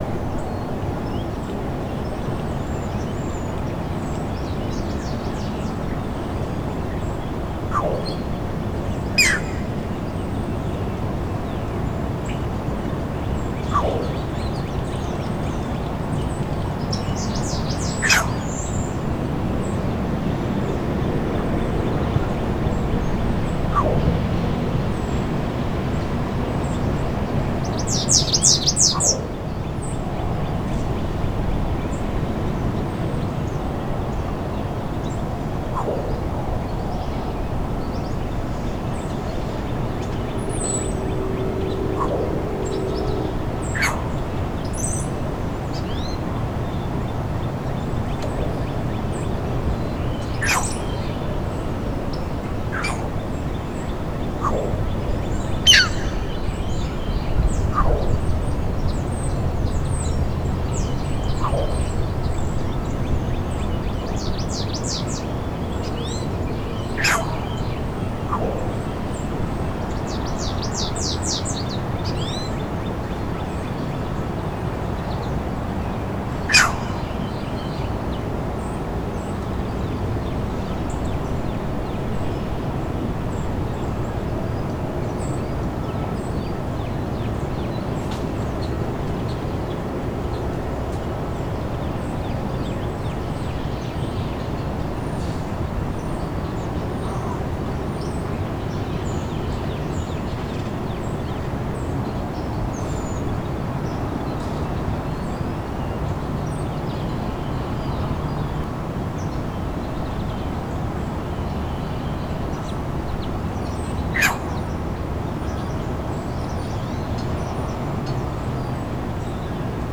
And this is the duet.
The male, here, emits the growl, and the female gives the response—a response that resembles the high-pitched Green Heron skeow flight/alarm call. I love it and am amused that the female gets the last word.
green-heron-courtship.wav